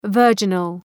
Προφορά
{‘vɜ:rdʒənəl}